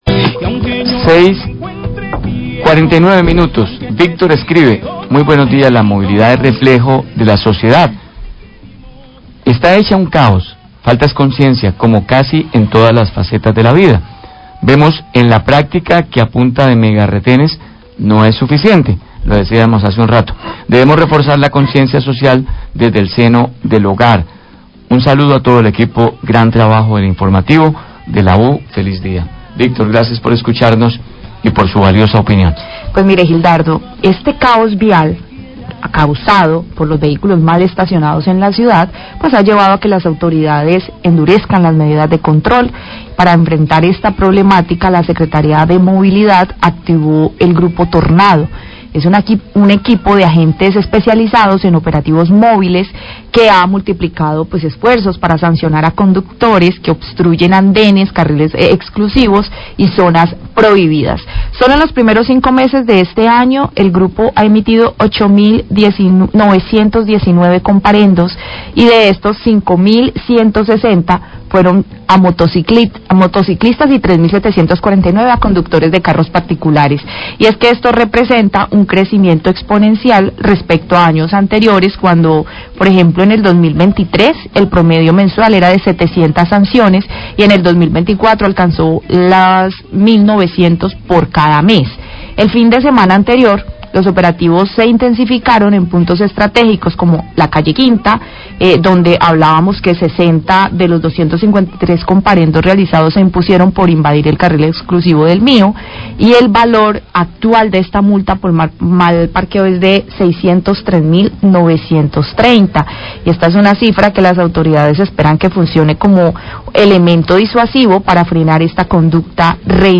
Radio
Secretaría de Movilidad de Cali informó las nuevas medidas que implementará para disminuir los casos que se presentan de invasión del espacio público en la ciudad. Por medio de una rueda de prensa, el secretario de Movilidad, Gustavo Orozco, expuso al Grupo Tornado, un conjunto de agentes de tránsito que, apoyados por la policía metropolitana, realizarán operativos relámpagos de control en las vías de la ciudad.